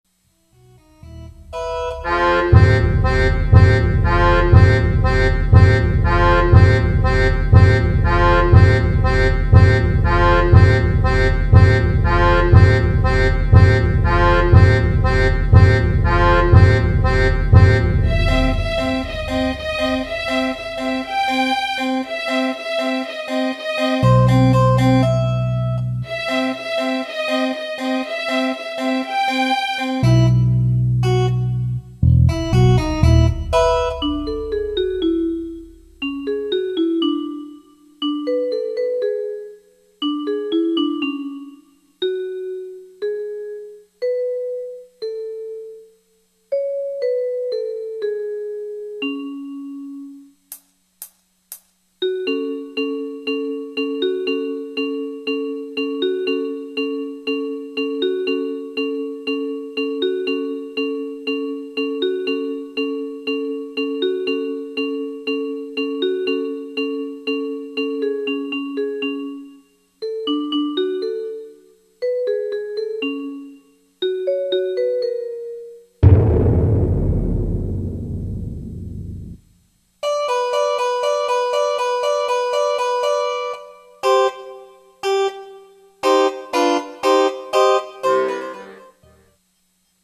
読み手:コラボ者様